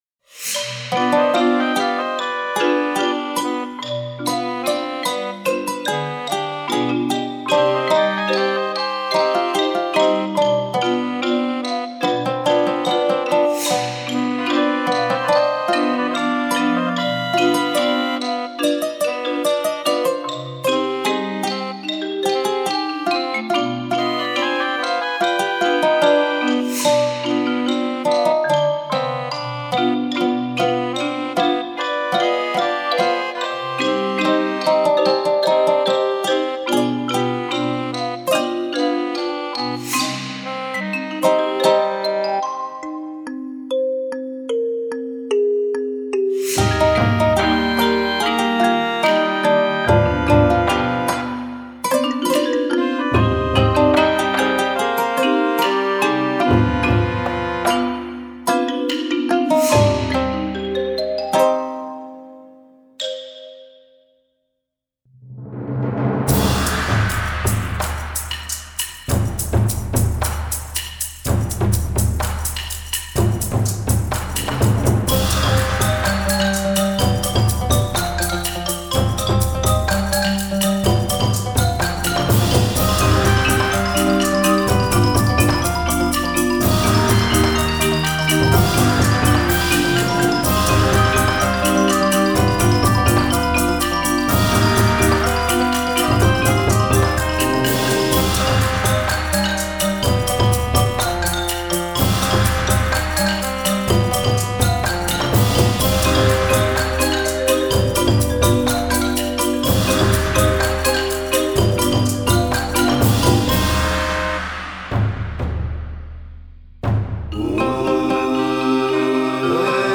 Ouverture